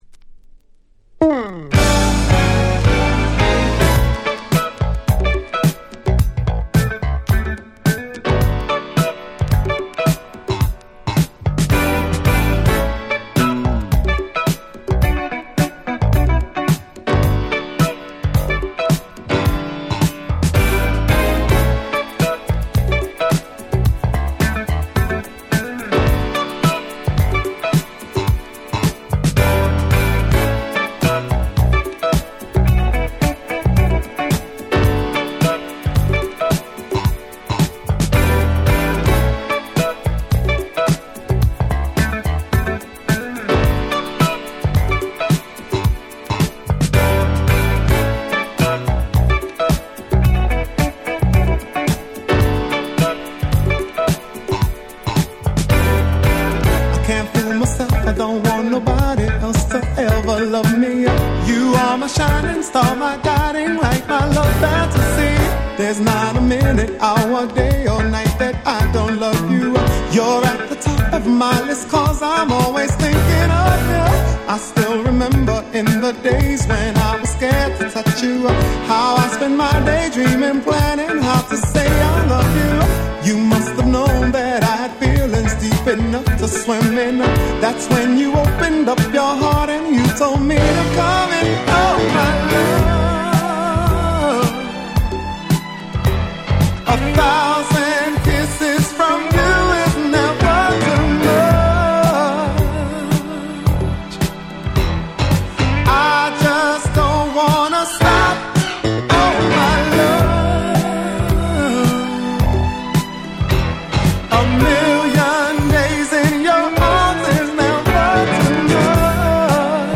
81' Super Hit Disco !!
ディスコ ダンクラ ダンスクラシックス